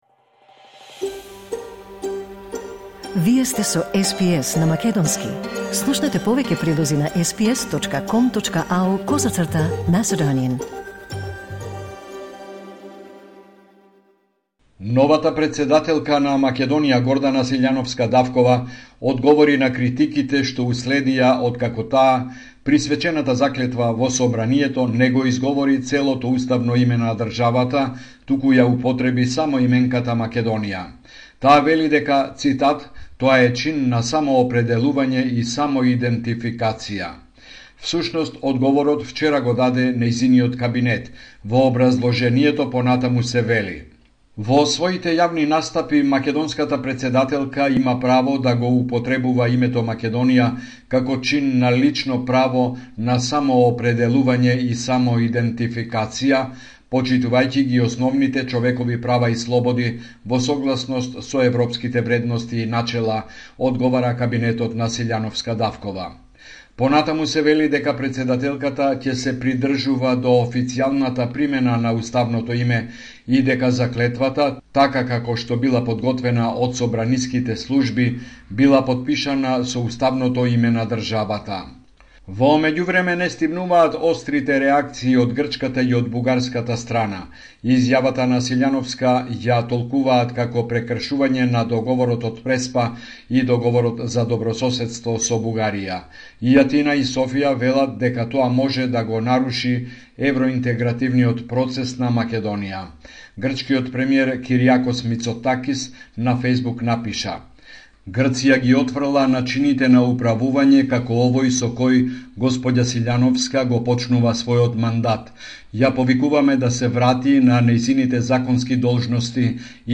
Homeland Report in Macedonian 14 May 2024